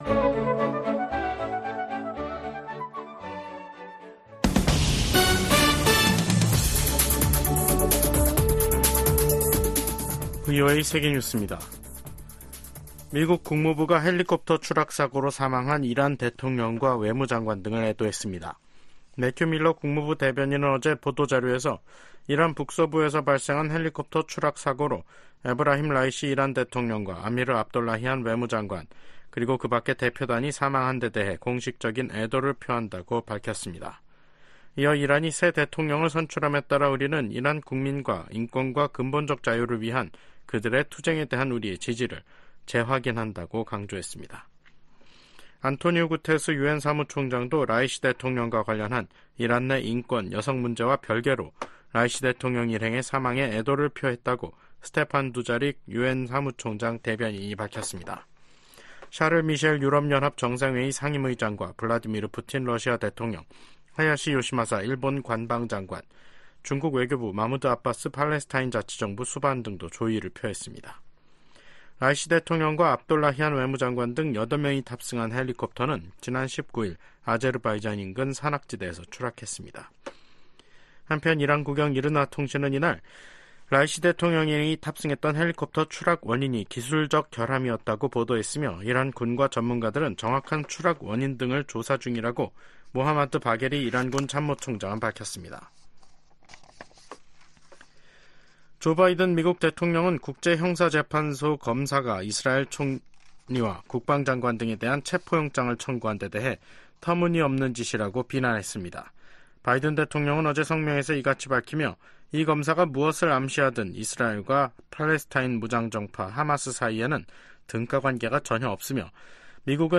VOA 한국어 간판 뉴스 프로그램 '뉴스 투데이', 2024년 5월 21일 3부 방송입니다. 최근 북한과 러시아의 협력 강화는 중국도 우려해야 할 사안이라고 미 국무부가 지적했습니다. 미국이 유엔 무대에서 북한과 러시아 간 불법 무기 이전을 비판하면서, 서방의 우크라이나 지원을 겨냥한 러시아의 반발을 일축했습니다. 유럽연합 EU는 러시아가 중국과의 정상회담 후 북한 옹호성명을 발표한 데 대해 기회주의적인 선택이라고 비판했습니다.